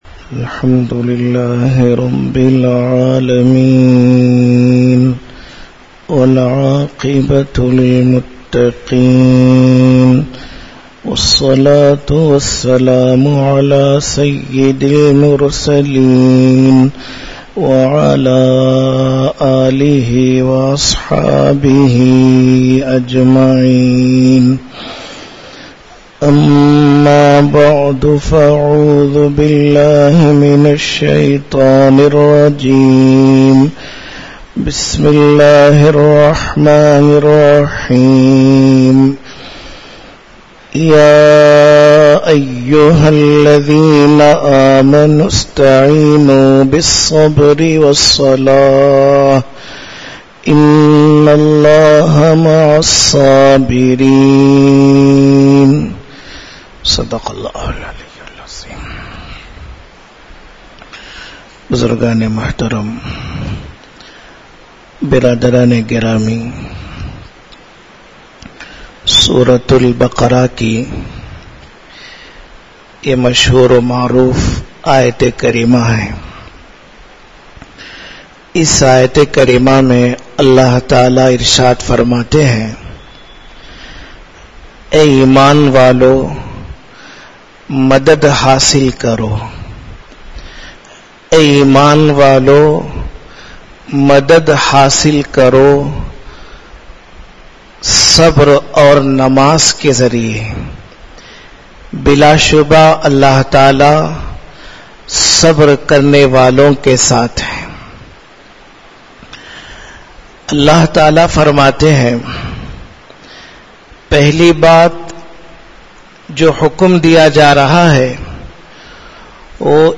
Majlis-e-Jamiulkhair, Jamiya Mosque, Ambur.